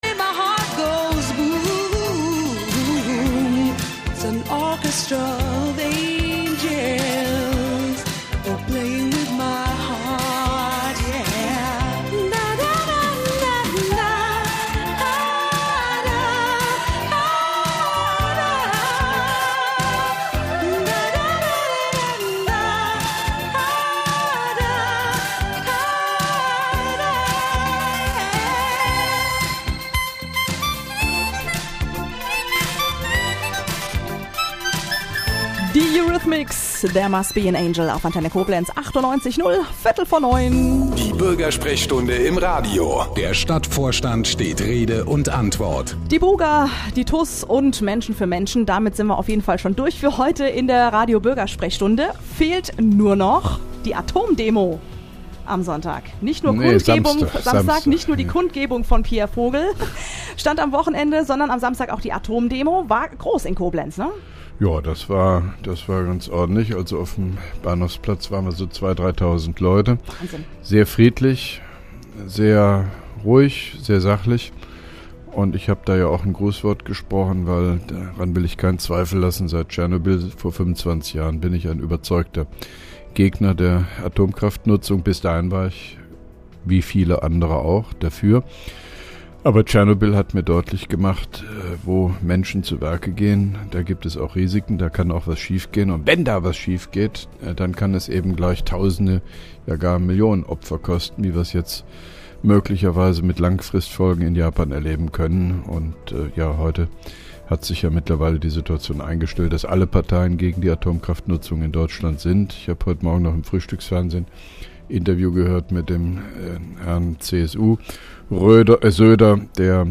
(3) Koblenzer Radio-Bürgersprechstunde mit OB Hofmann-Göttig 31.05.2011